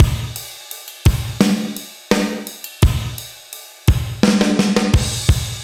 Index of /musicradar/80s-heat-samples/85bpm
AM_GateDrums_85-03.wav